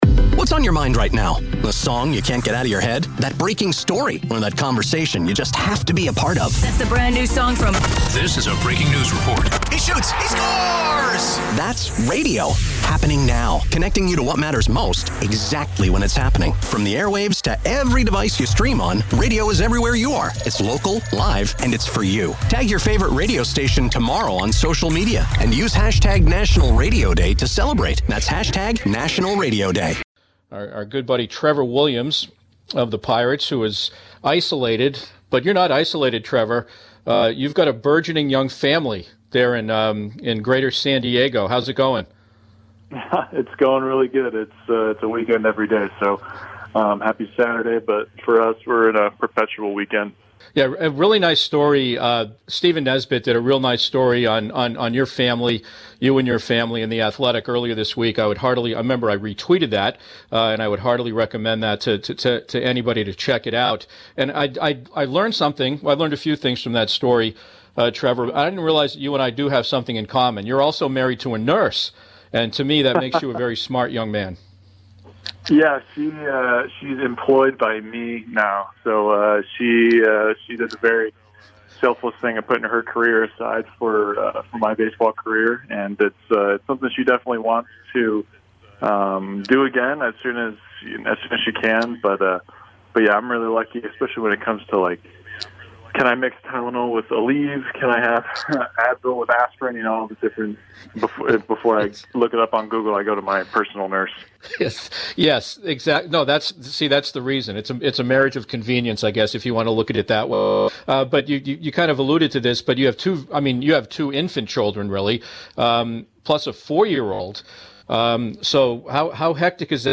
one-on-one interviews with athletes andsports newsmakers in Pittsburgh